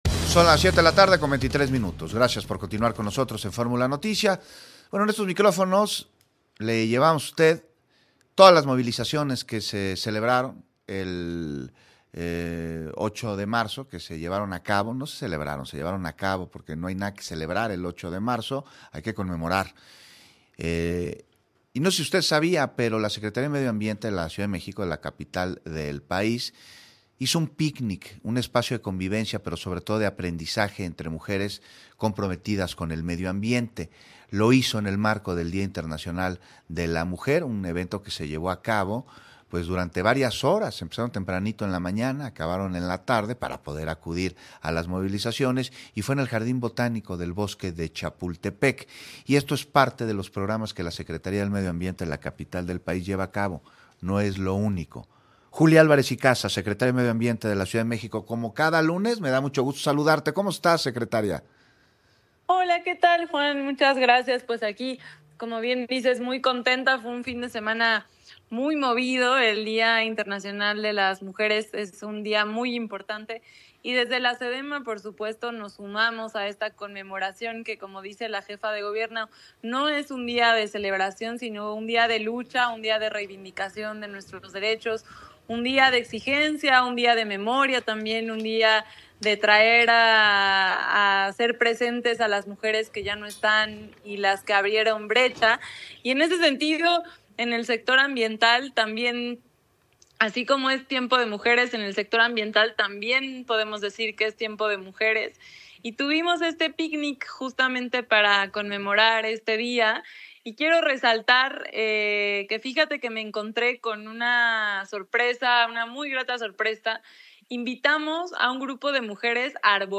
Entrevista a Julia Álvarez Icaza, Titular de SEDEMA en el marco del 8M